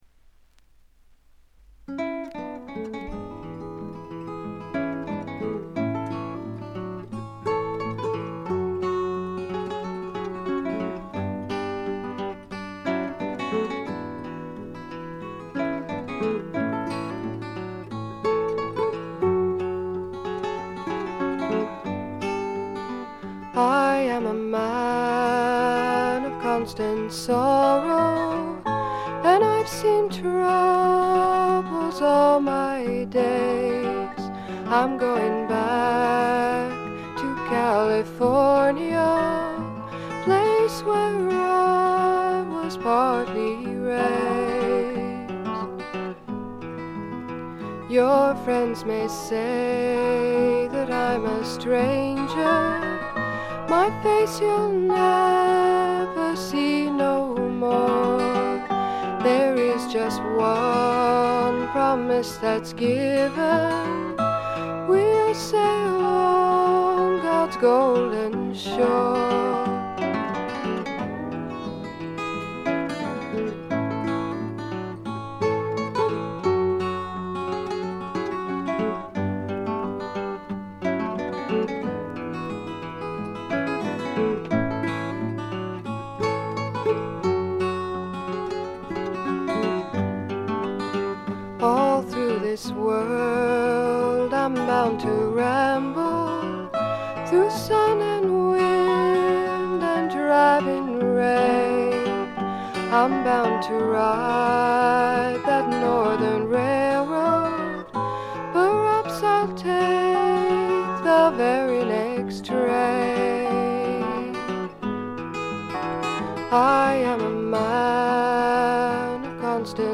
ちょっとハスキーな美声ではかなげに歌われる宝石のような歌の数々。
試聴曲は現品からの取り込み音源です。
guitar
flute
dobro guitar